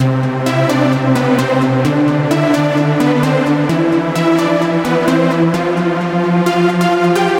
描述：主导舞蹈的旋律
Tag: 130 bpm Dance Loops Synth Loops 1.24 MB wav Key : Unknown